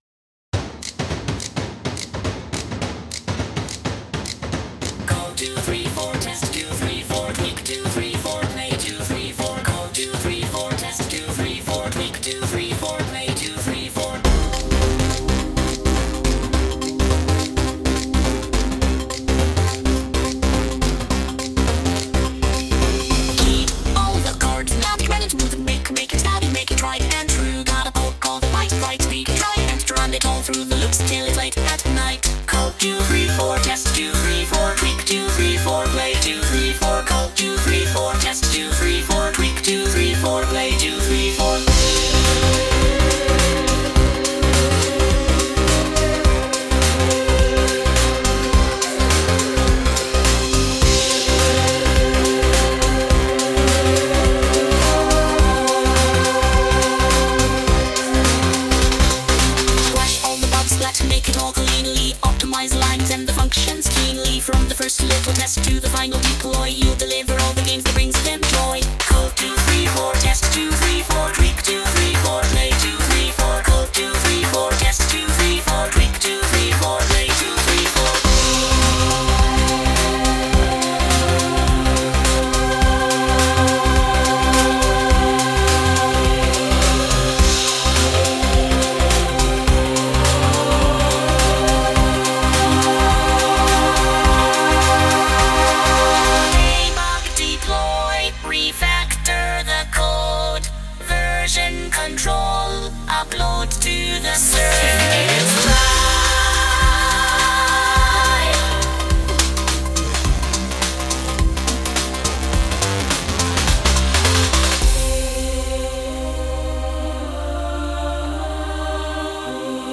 Sung by Suno
Code,_Two,_Three,_Four_(Cover)_mp3.mp3